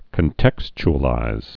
(kən-tĕksch-ə-līz)